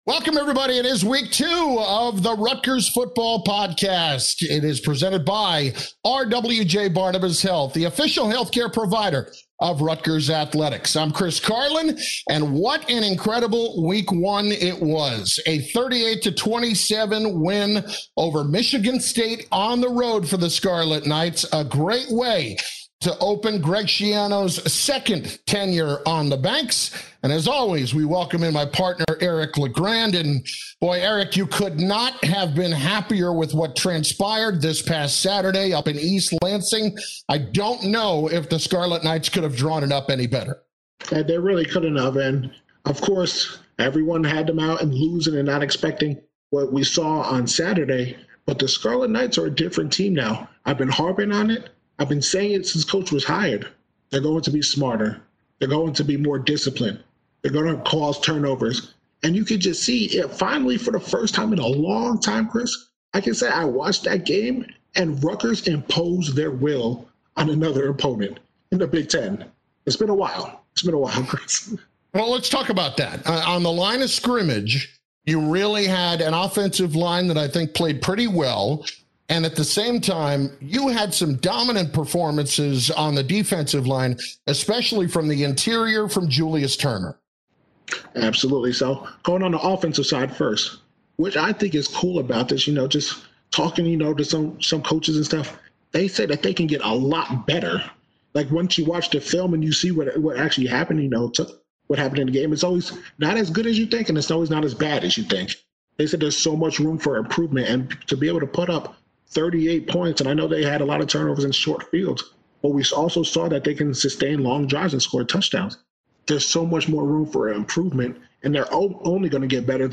Each week across the Rutgers Sports Network from Learfield IMG College, Chris Carlin and Eric LeGrand talk Rutgers football.